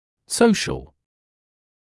[‘səuʃl][‘соушл]социальный, общественный